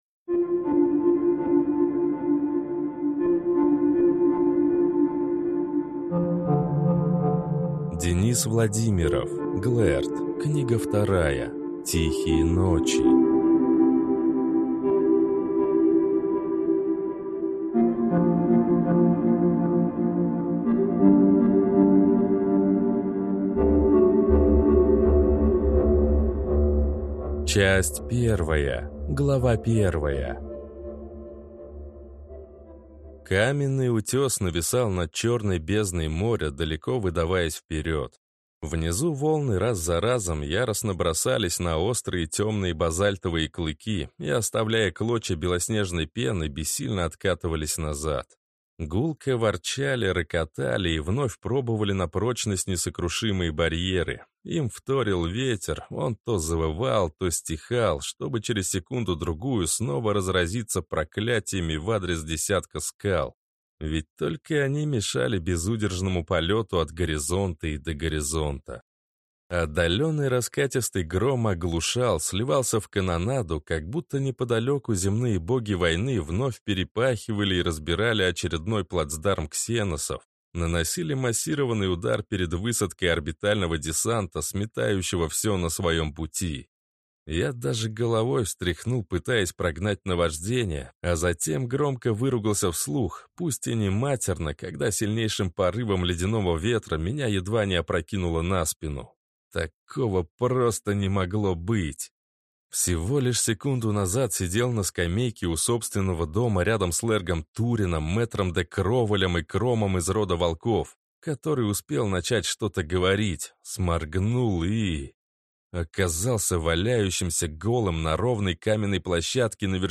Аудиокнига Глэрд. Книга 2. Тихие ночи | Библиотека аудиокниг